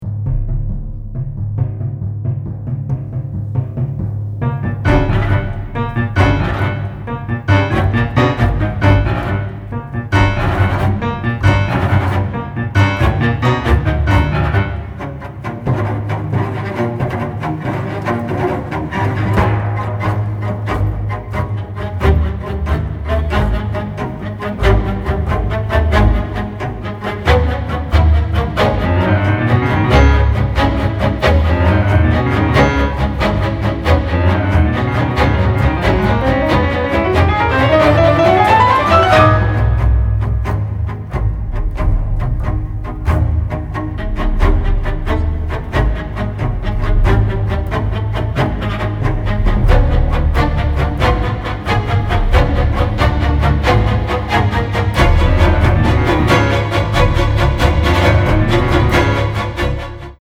Recorded at Air Studios Lyndhurst Hall in London